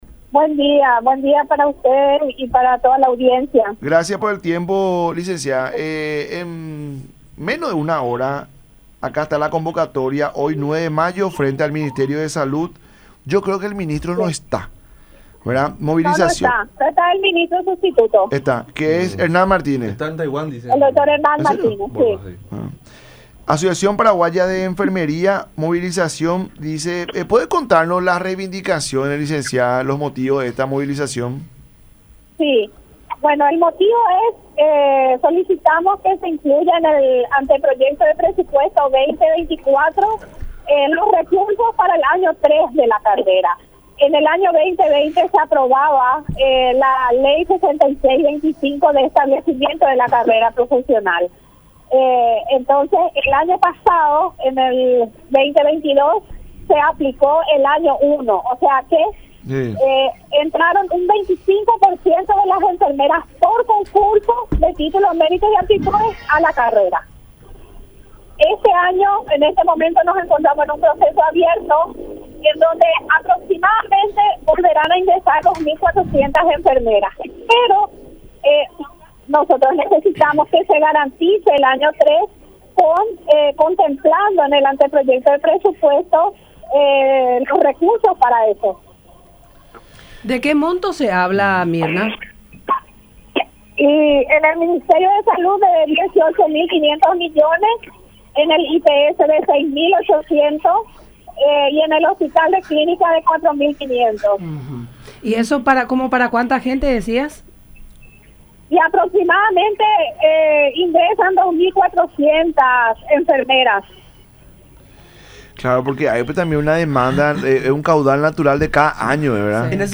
en charla con La Mañana De Unión por Unión TV y radio La Unión